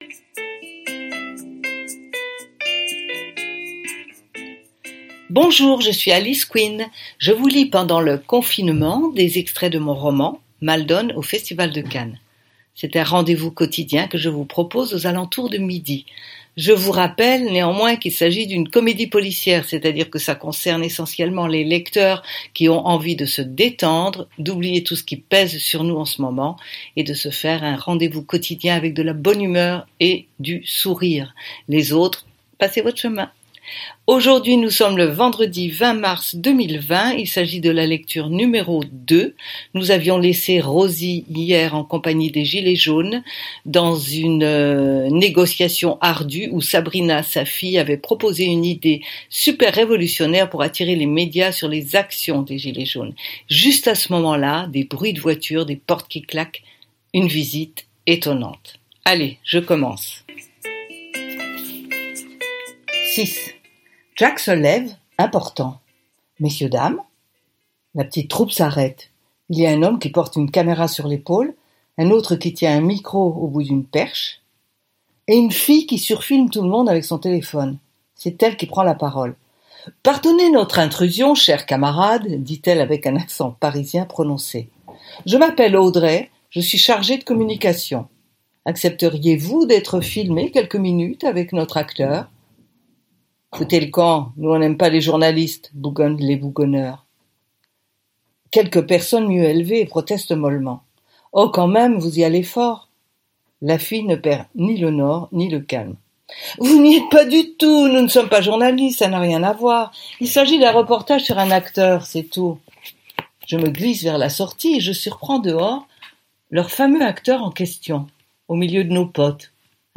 Lecture #2 Ce roman fait partie de la série AU PAYS DE ROSIE MALDONNE.